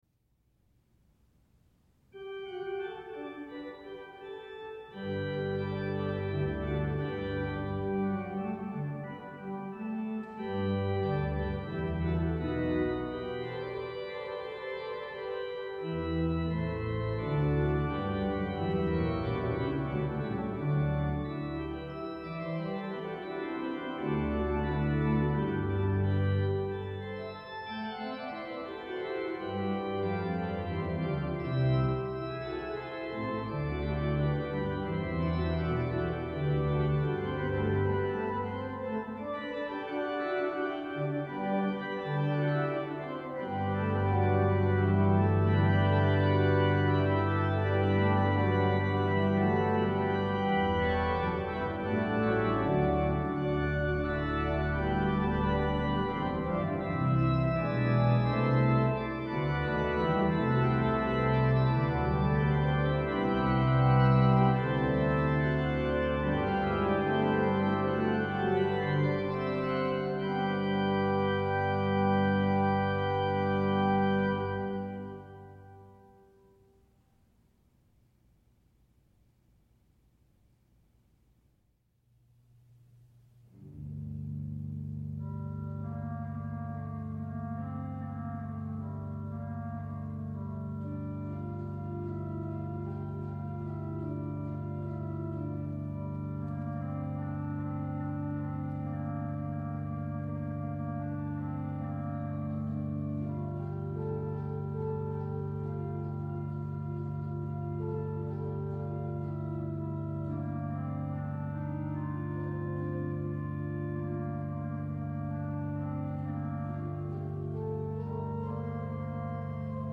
• Music Type: Organ
• Imaginative suite of pieces based on Thanksgiving tunes